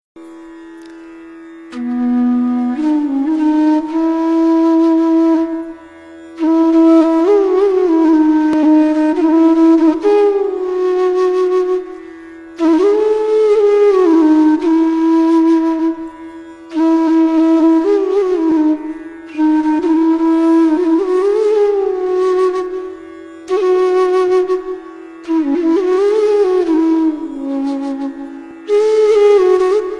flute basuri Category